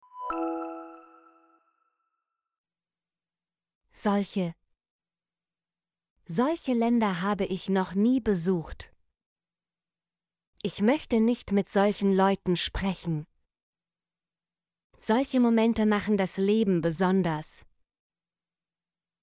solche - Example Sentences & Pronunciation, German Frequency List